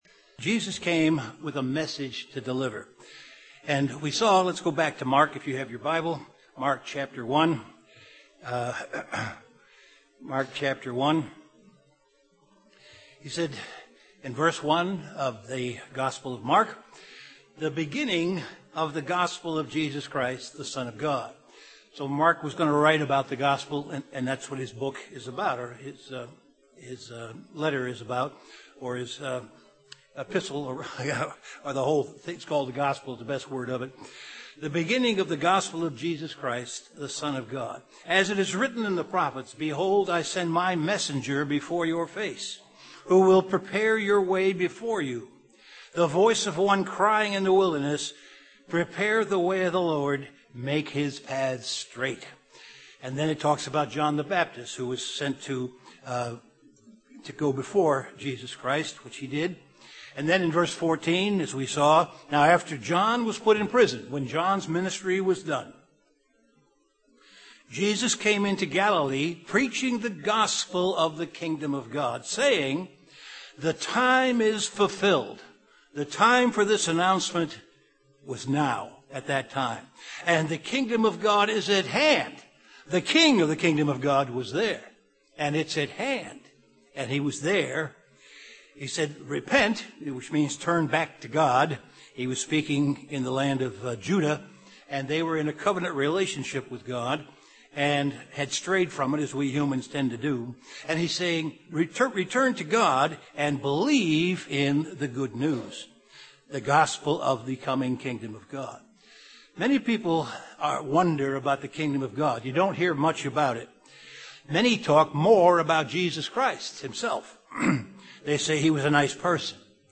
A Kingdom of God Bible Seminar Presentation
Given in Chicago, IL
UCG Sermon Studying the bible?